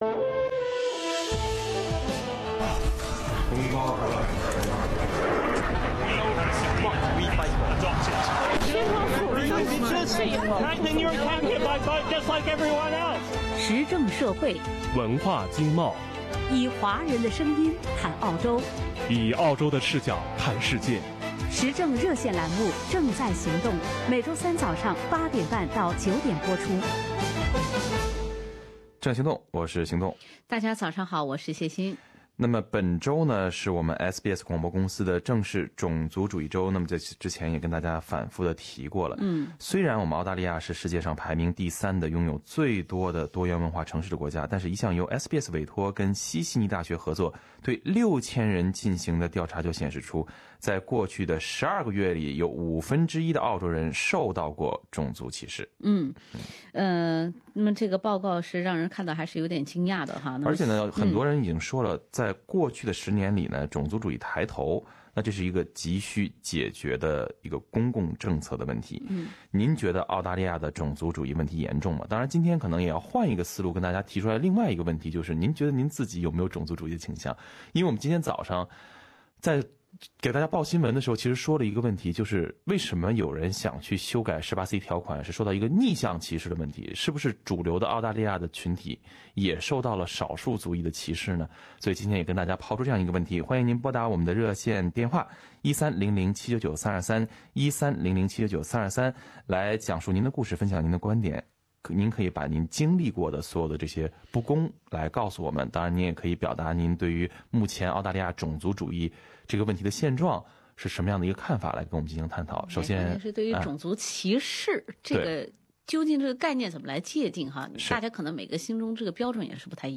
正在行动热线接不停：澳大利亚是种族主义国家吗？